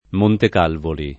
[ montek # lvoli ]